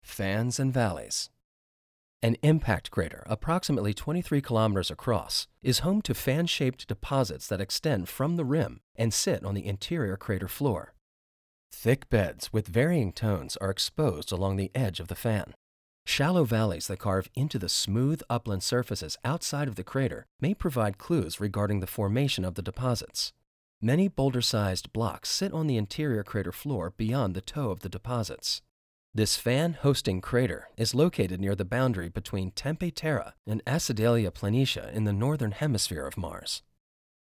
narration